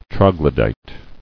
[trog·lo·dyte]